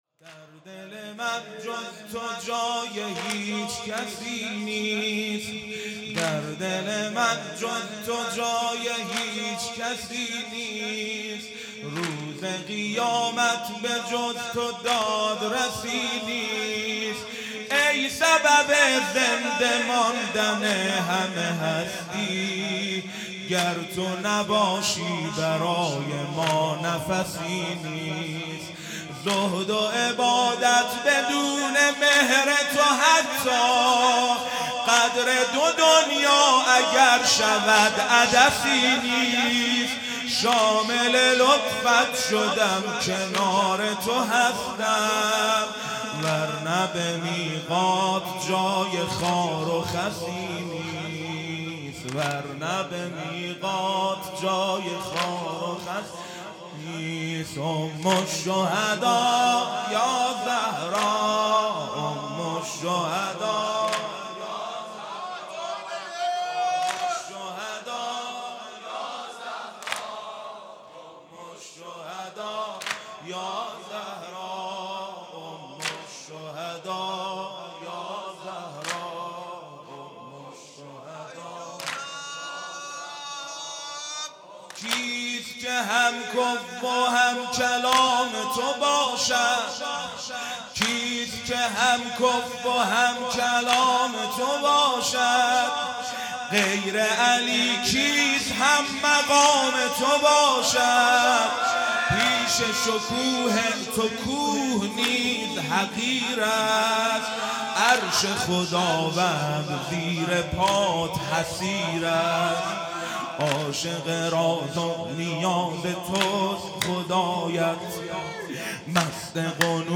در دل من جز تو جای کسی نیست|جلسه هفتگی